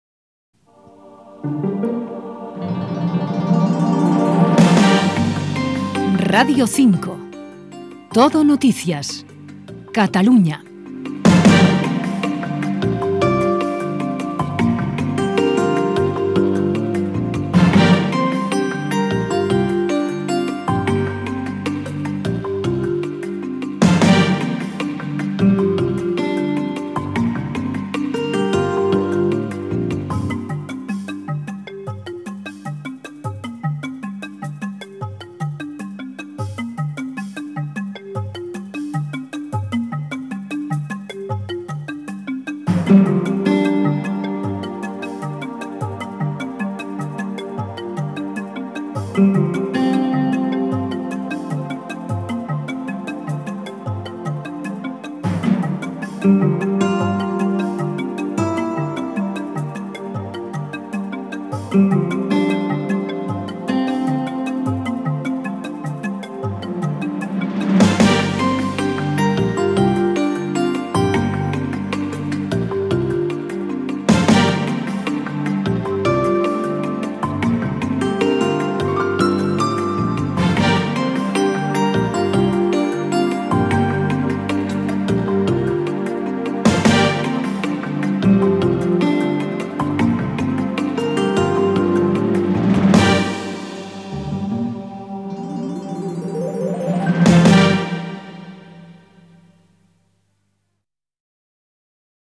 Indicatiu de l'emissora i cua musical
FM